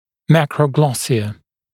[ˌmækrəu’glɔsɪə][ˌмэкроу’глосиэ]макроглоссия, аномально большой язык